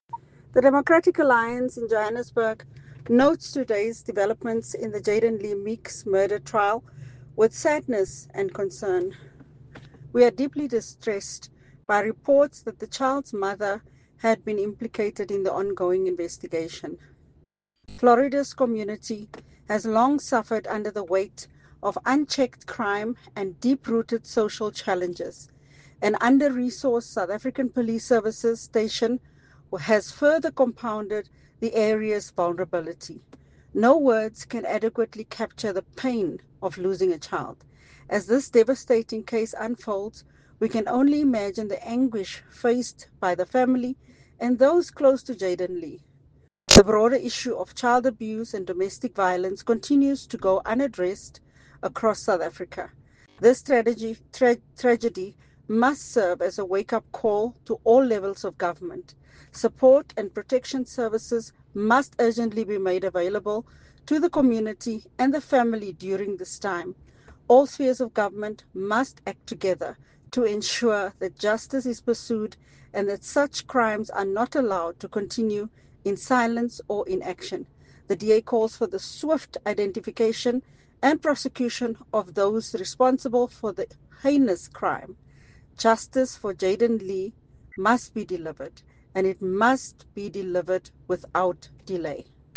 Note to Editors: Please find an English soundbite by Cllr Belinda Kayser-Echeozonjoku